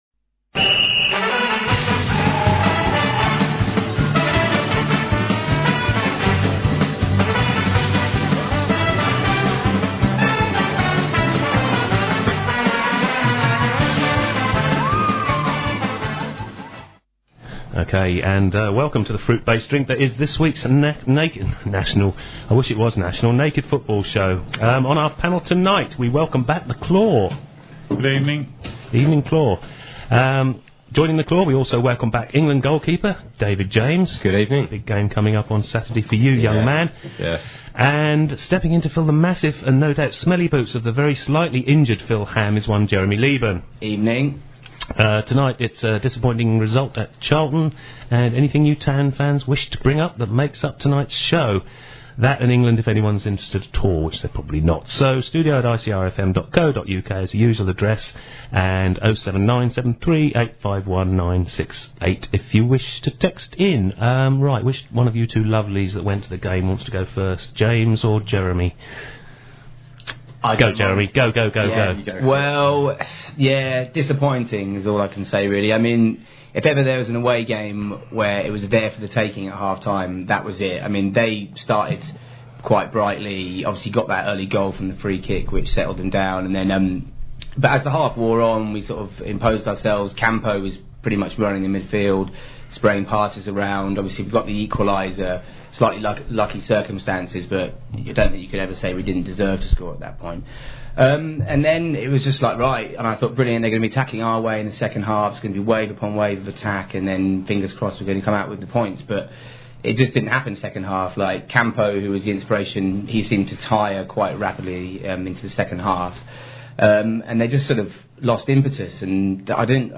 The programme goes out live at 6pm every Wednesday on Ipswich Community Radio at 105.7FM if in the Ipswich area or online if not.